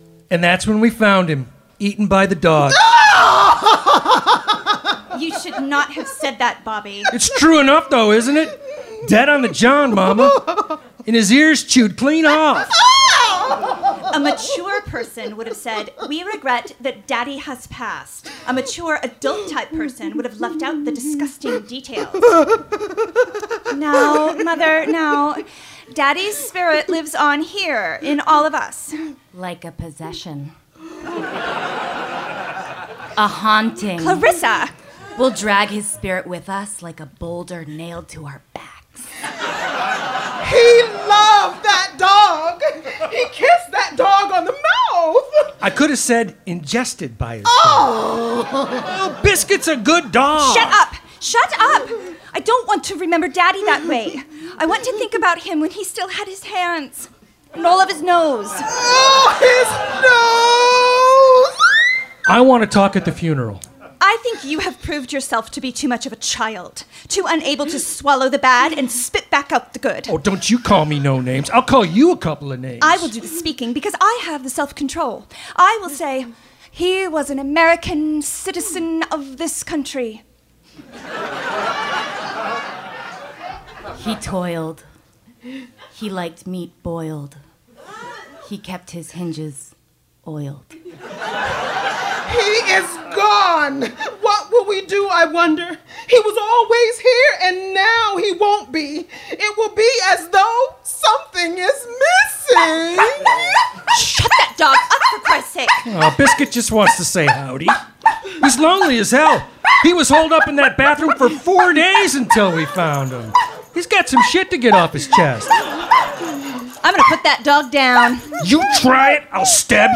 Recorded at The Bathhouse Theater Seattle on Oct 30, 2017.